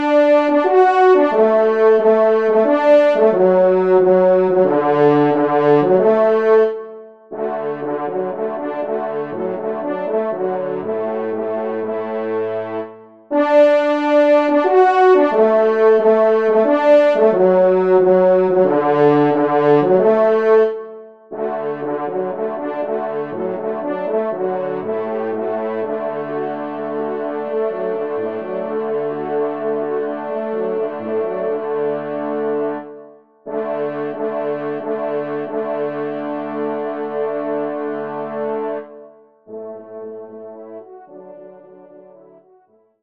Genre : Divertissement pour Trompes ou Cors
Trompe 3  (en exergue)